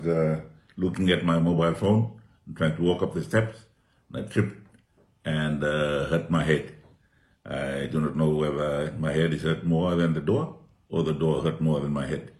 In a video message, Rabuka says he sustained an injury to his head as a result of a misstep at the entrance to the New Wing of Government Buildings.